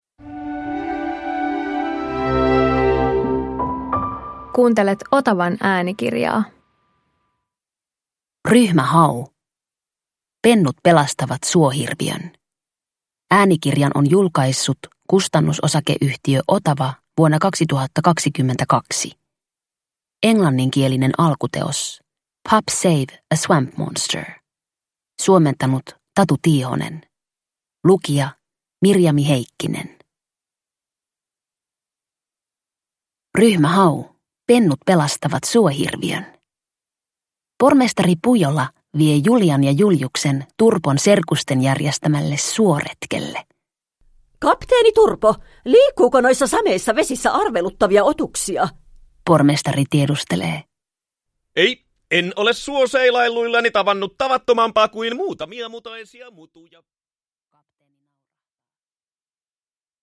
Jännittävä äänikirja Ryhmä Haun pelastustehtävästä.